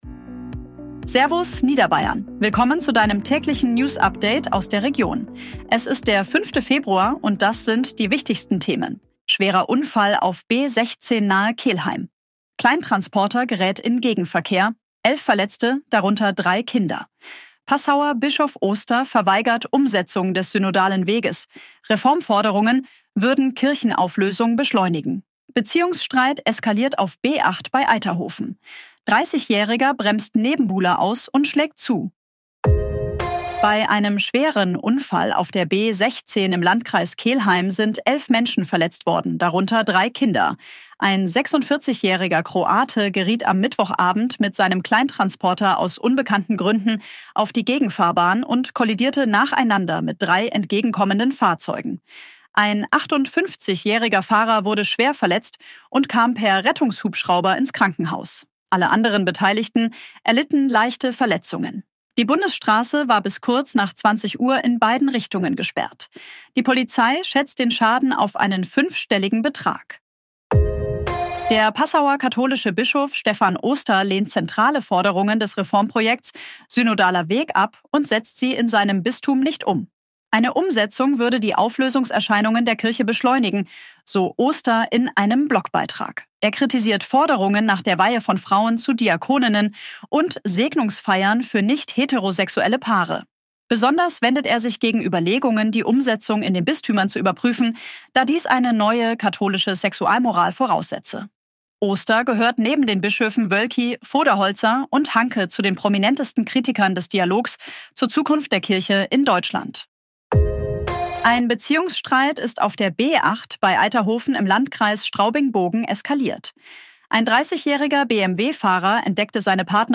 Tägliche Nachrichten aus deiner Region
es schon 55.000 Euro für die drei Kinder Dieses Update wurde mit